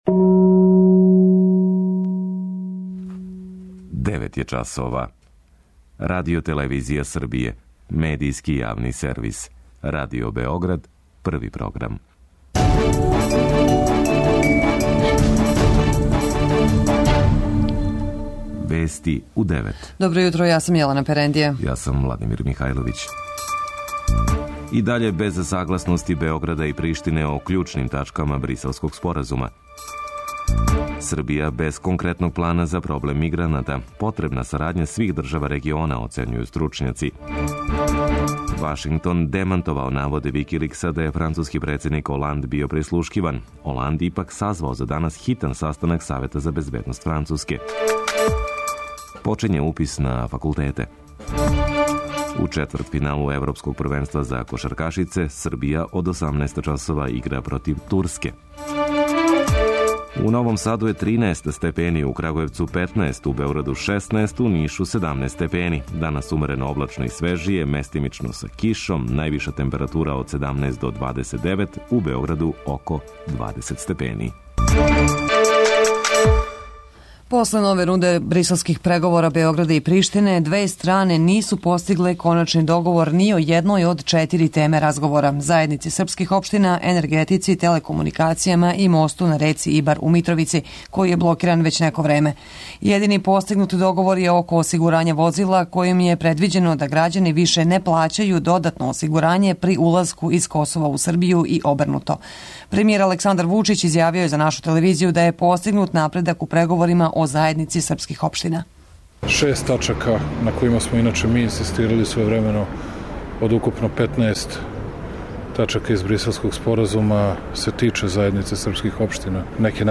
vesti_24-06.mp3